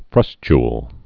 (frŭschl, -tyl)